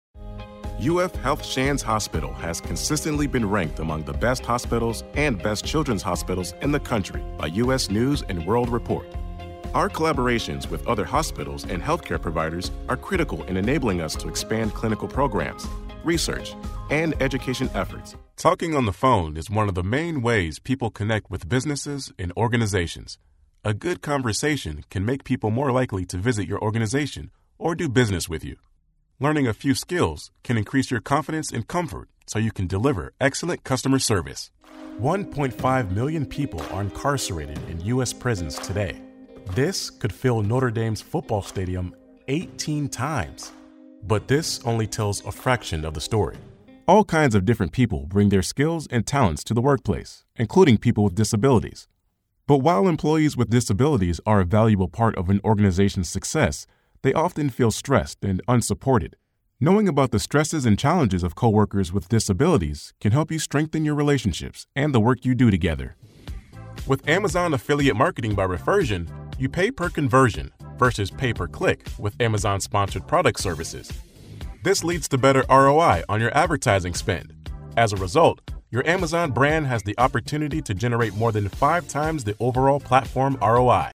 English (American)
Deep, Urban, Friendly
Corporate